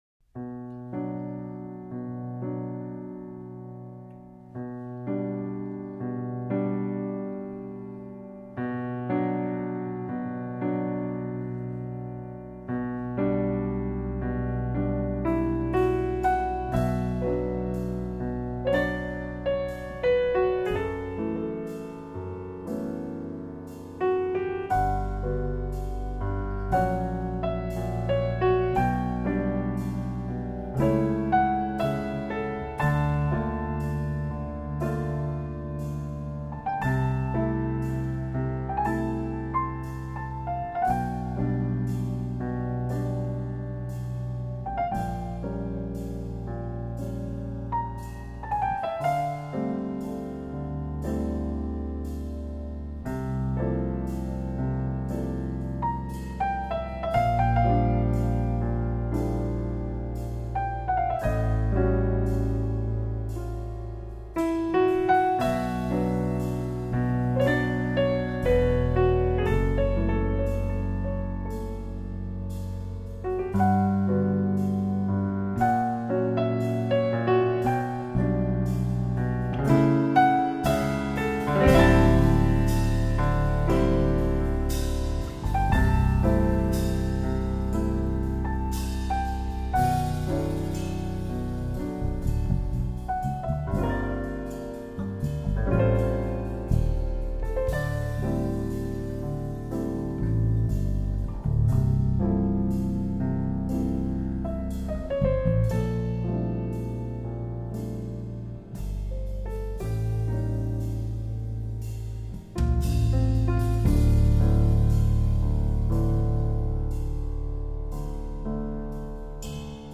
superbe ballade